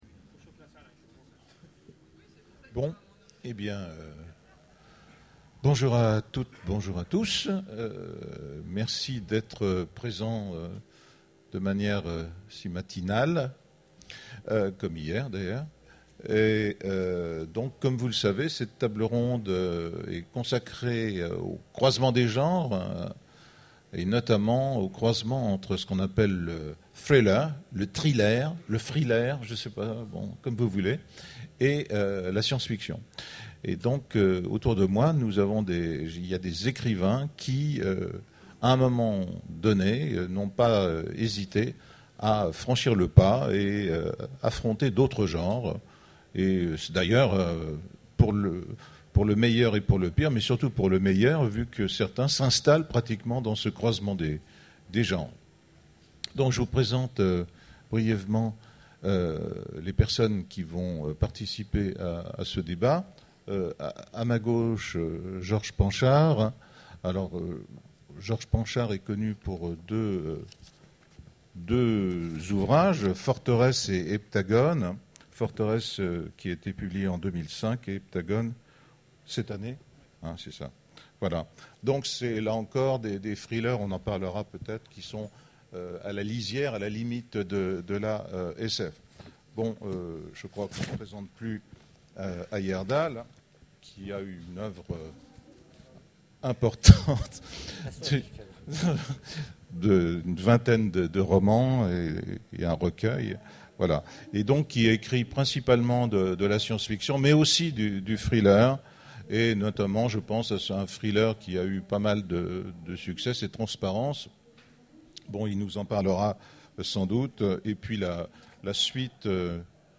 Utopiales 12 : Conférence Thriller et science-fiction